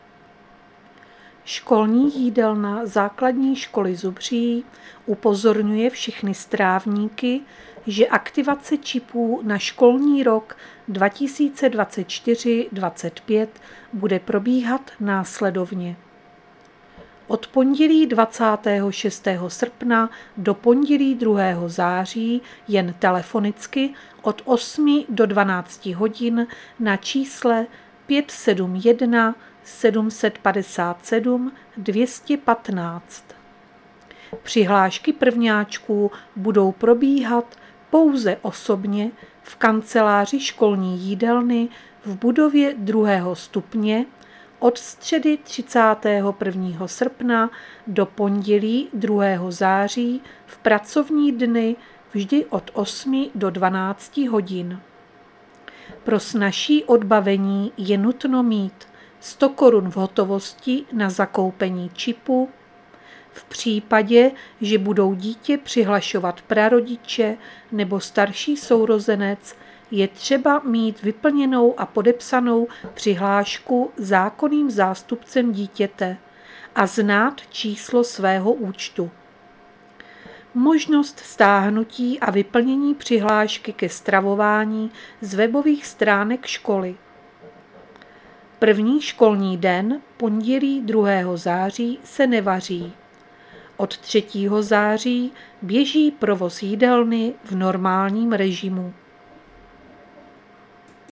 Záznam hlášení místního rozhlasu 22.8.2024